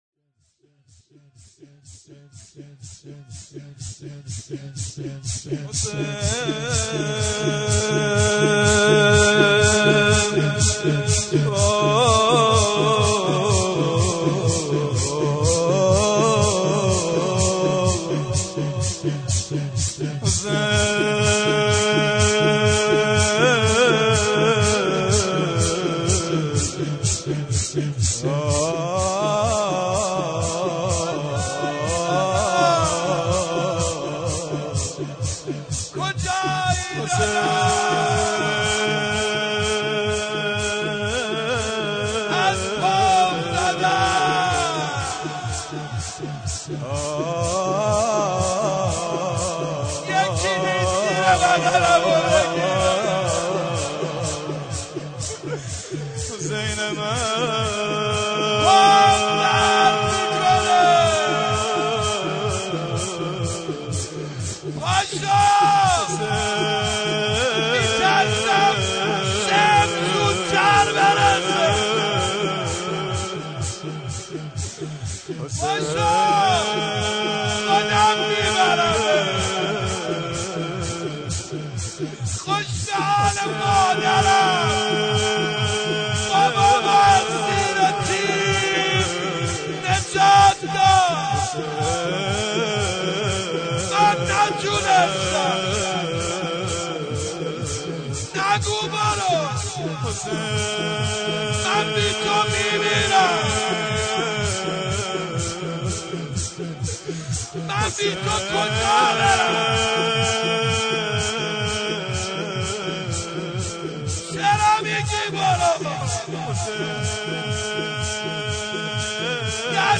در مهدیه امام حسن مجتبی(ع) برگزار گردید.
دعا و مناجات
سینه زنی